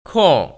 [kòngr] 쿠얼